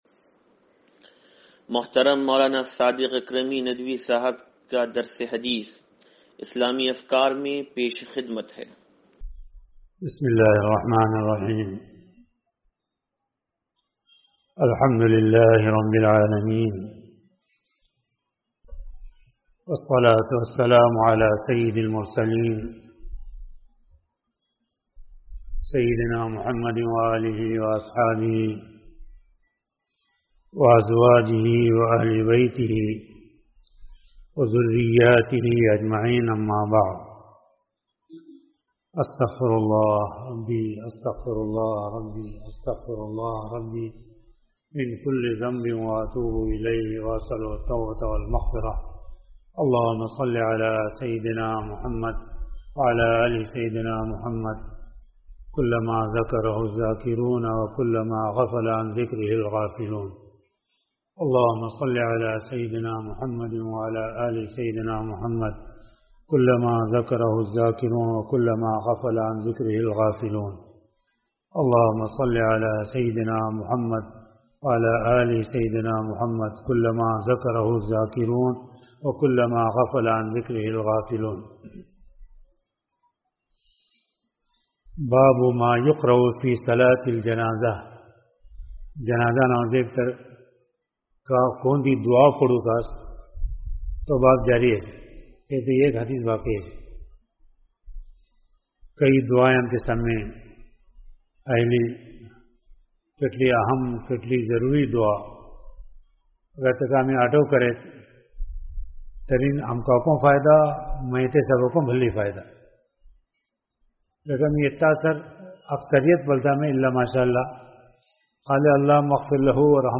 درس حدیث نمبر 0738